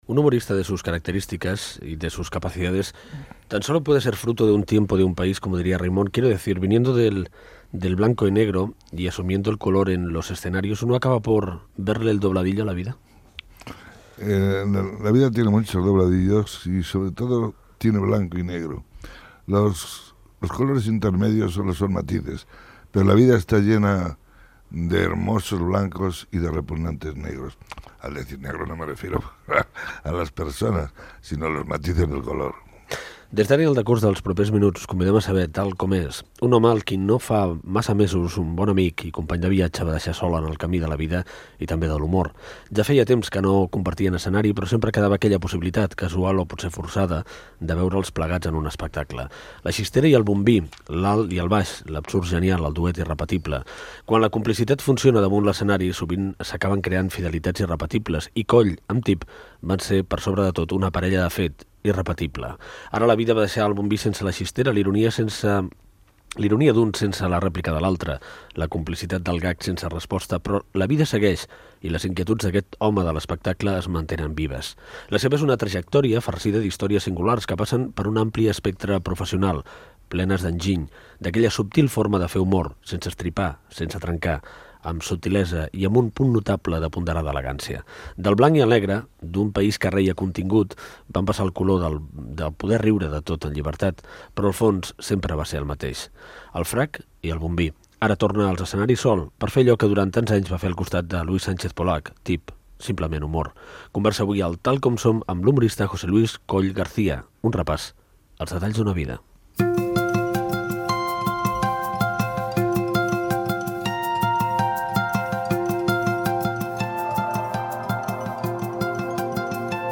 Fragment d'una entrevista a l'humorista José Luis Coll.
Entreteniment
FM